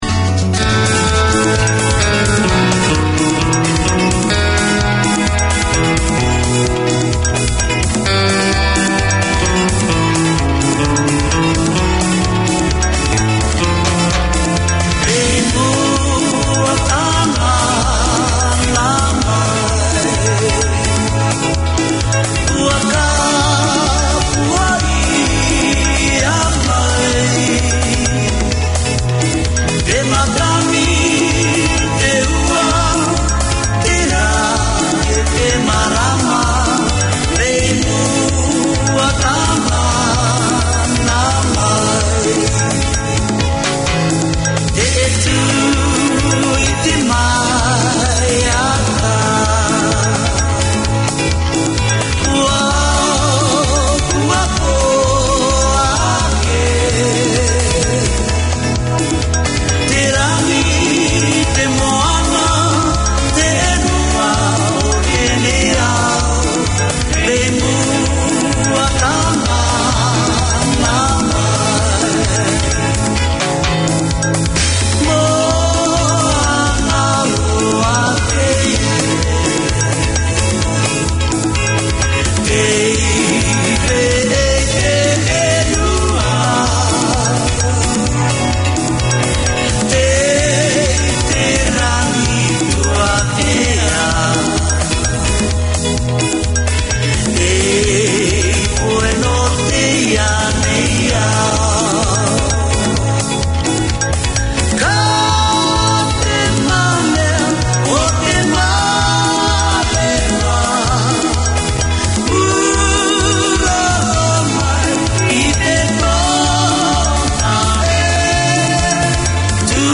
Radio made by over 100 Aucklanders addressing the diverse cultures and interests in 35 languages.
An outreach of the Pacific Islands Health and Welfare project under the auspices of the Auckland Health Board, Cook Islands Health is the half hour each week that keeps you in touch with health news, with interviews, information, community updates.